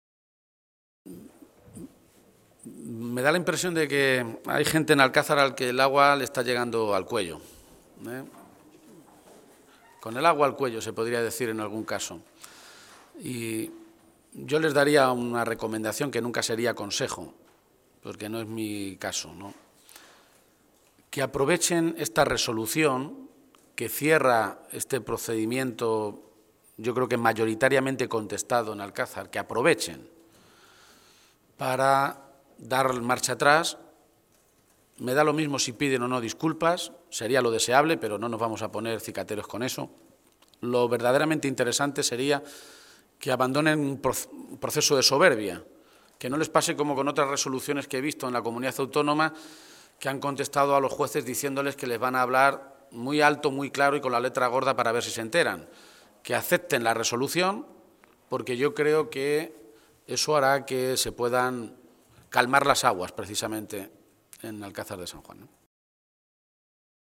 García-Page se pronunciaba de esta manera esta mañana, en Toledo, a preguntas de los medios de comunicación, y añadía que le daba la impresión de que “hay gente en Alcázar a la que el agua le está llegando al cuello.
Cortes de audio de la rueda de prensa